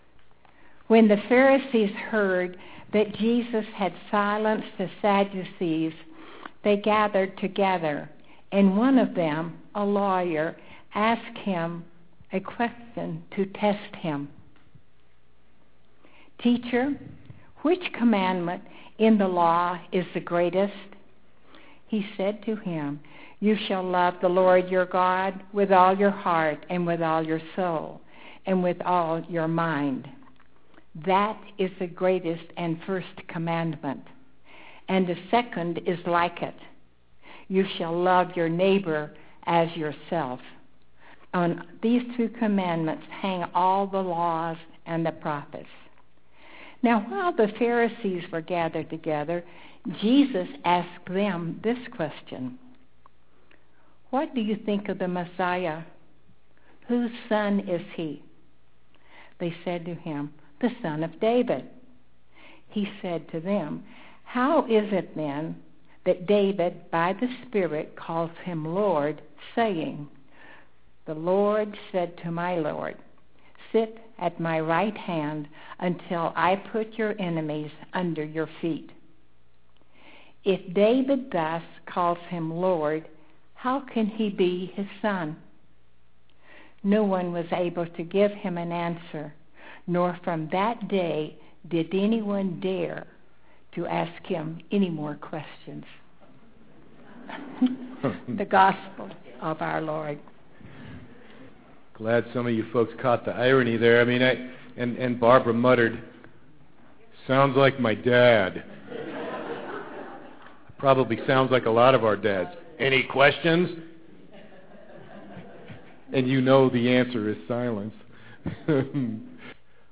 Click on the white triangle in the black bar-shaped player below to listen to the scripture and sermon now or click on the link labeled: Download below the player to save an audio mp3 file for listening later. http